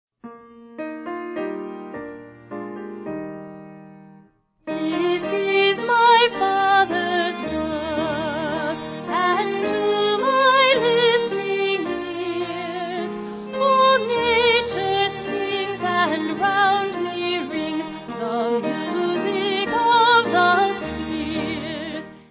Choir
3/Eb